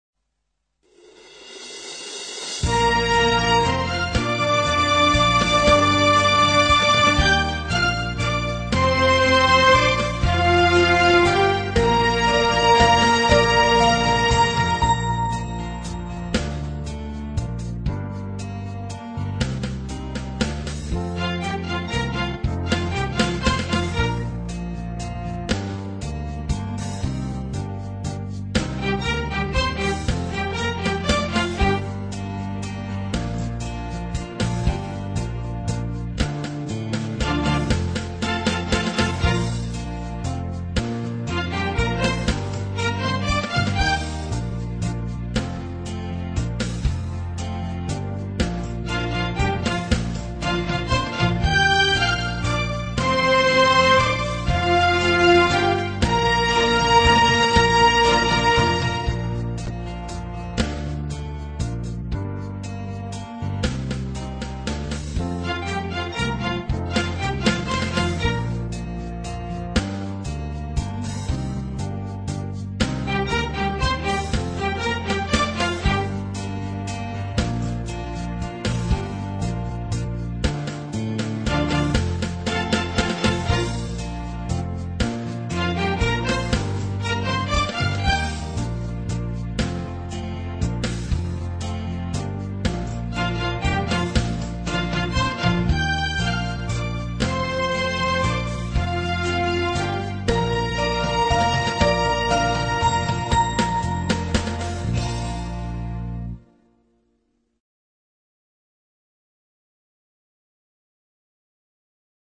无 调式 : 降B 曲类 : 民族